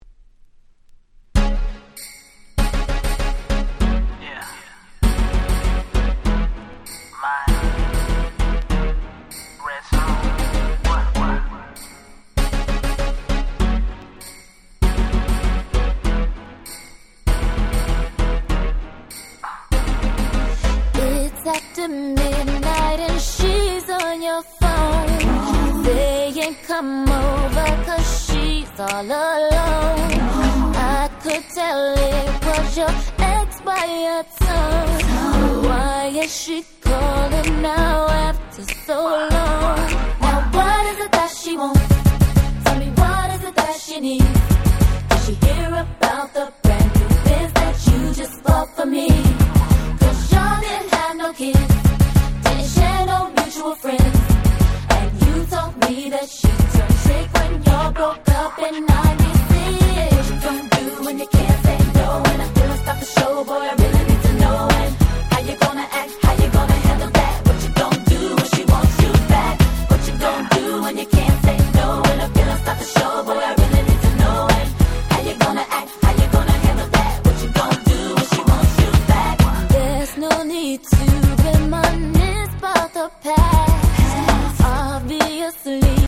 00' Super Hit R&B !!
まだまだ若さ溢れる可愛いR&Bチューン！！